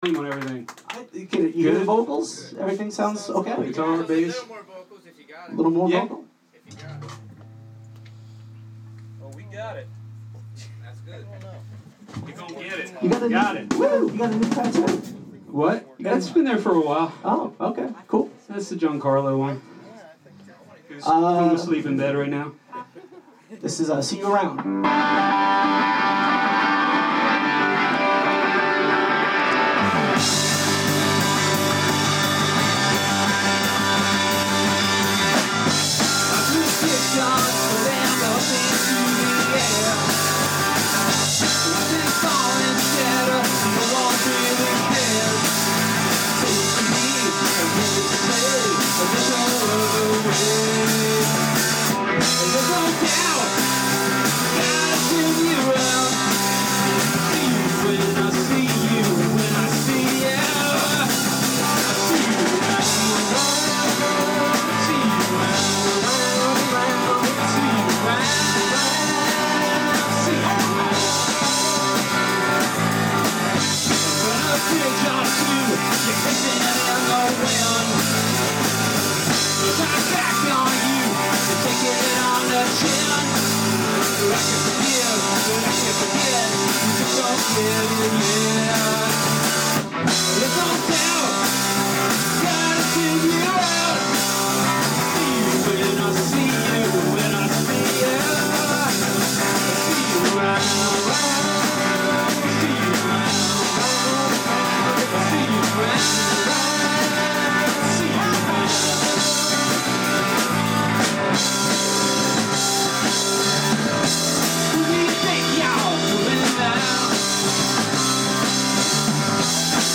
record release party